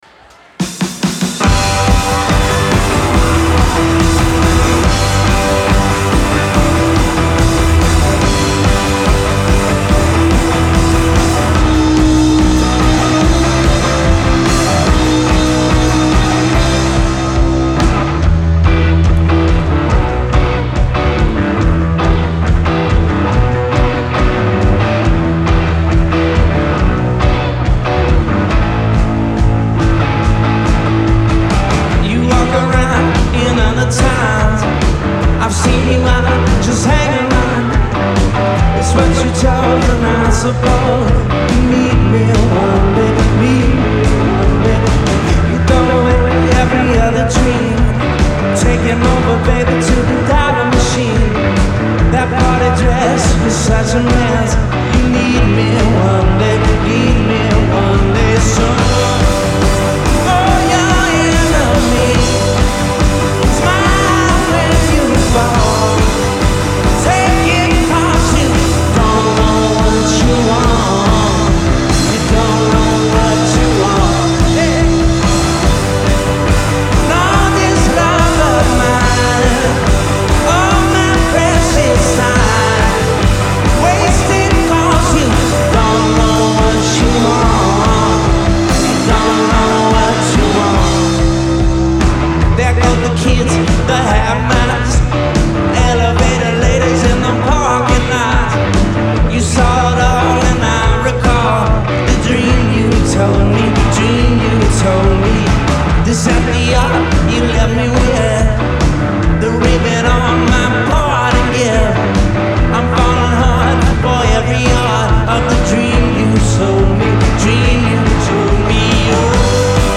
Genre : Alternative, Indie
Live in Portland, ME